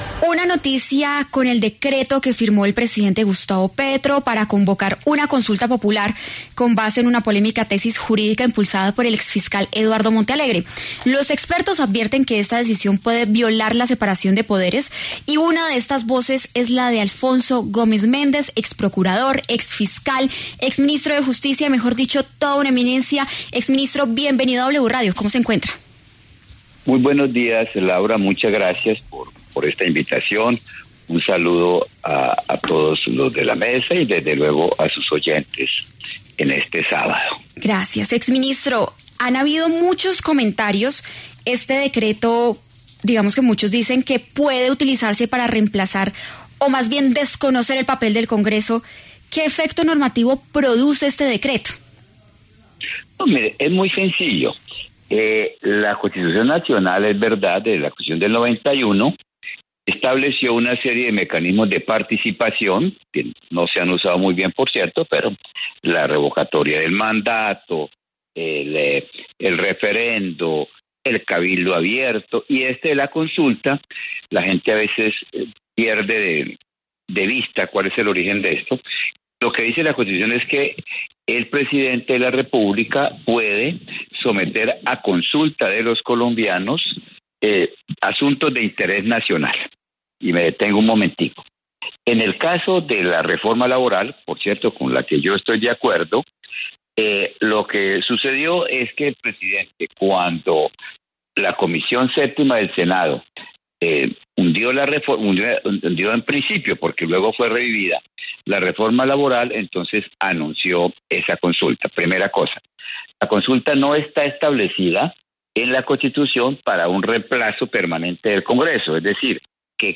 El exfiscal Alfonso Gómez Méndez conversó con W Fin de Semana a propósito de la firma del Decreto 0639 el pasado 11 de junio por parte del presidente Gustavo Petro, con el cual convoca a una consulta popular nacional para el 7 de agosto, con 12 preguntas centradas en las reformas laboral y a la salud.